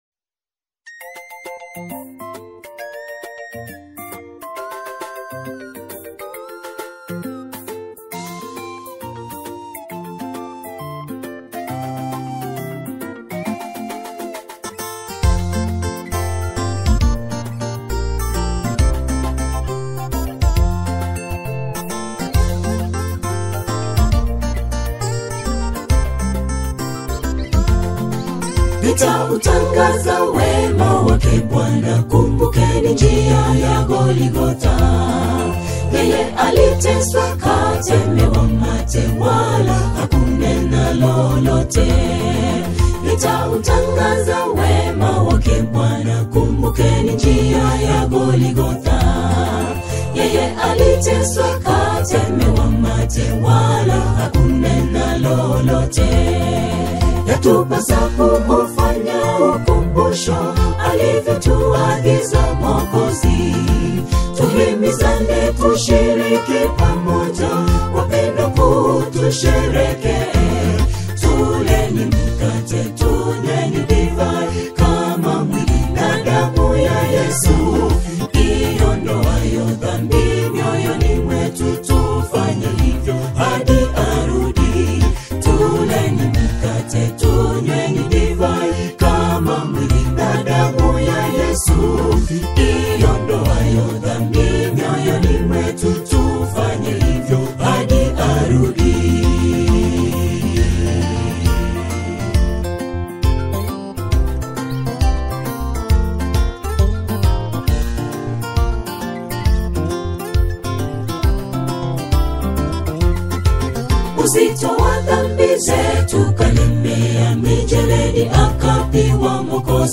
a triumphant and doctrinally rich single
soul-stirring melodies.